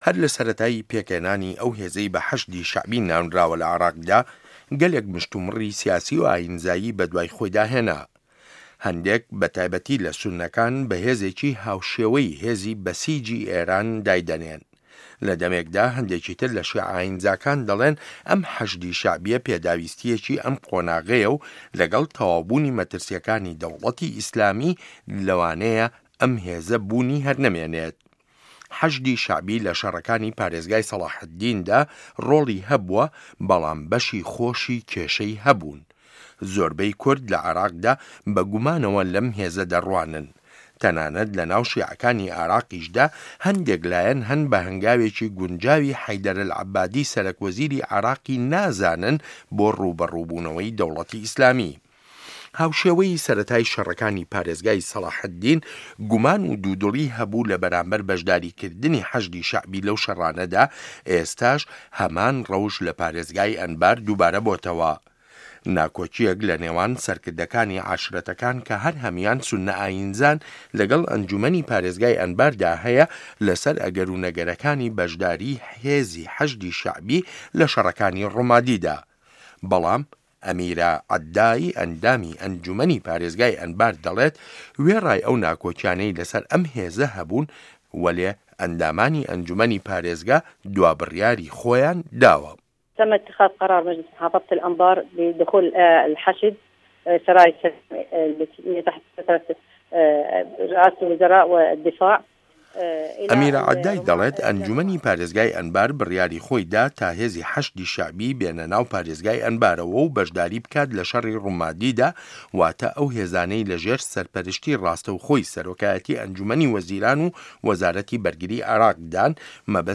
ڕاپۆرتی حه‌شدی شه‌عبی به‌ره‌و ڕومادی